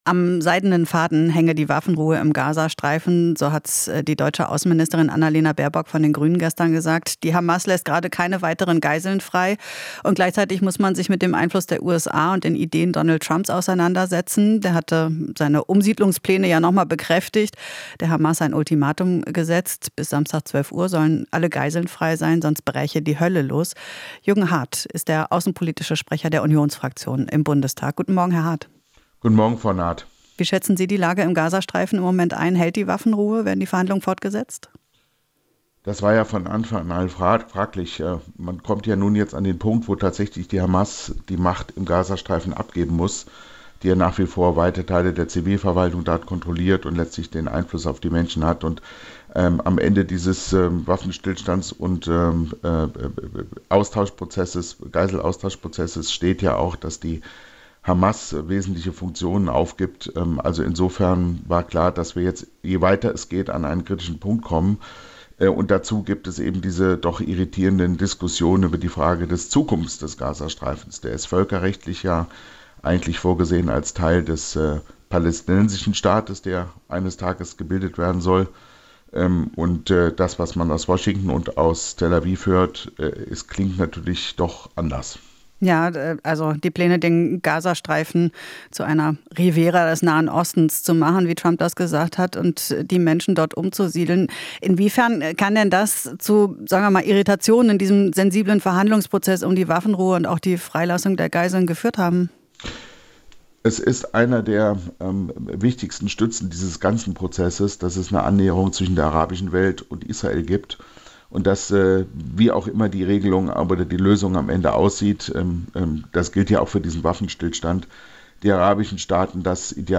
Interview - Hardt (CDU): Vereinbarungen in Gaza müssen weiter umgesetzt werden